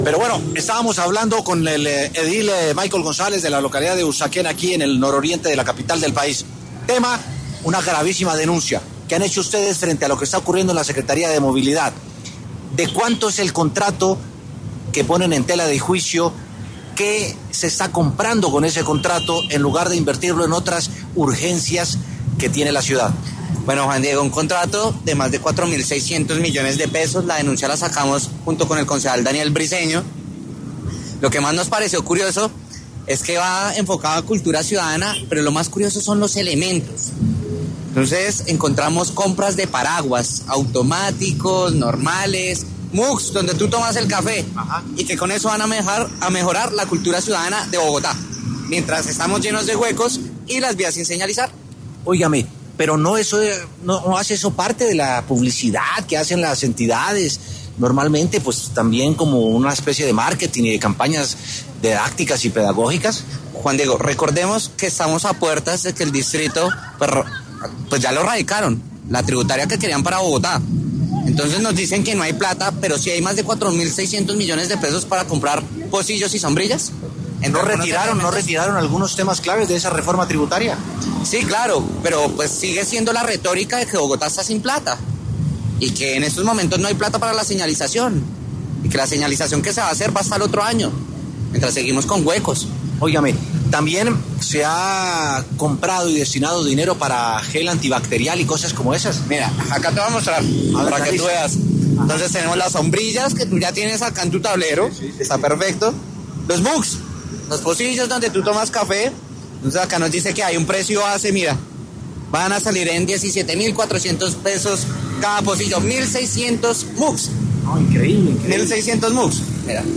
Es por esto que W Sin Carreta se contactó con el edil de Usaquén, Michael González, quien dio más detalles sobre esta denuncia.